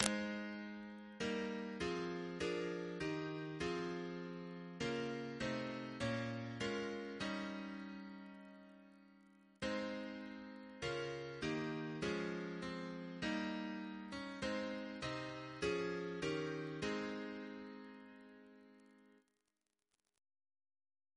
Double chant in A♭ Composer: Sir Charles V. Stanford (1852-1924), Professor of Music, Cambridge Reference psalters: ACB: 387; ACP: 313; CWP: 21; RSCM: 127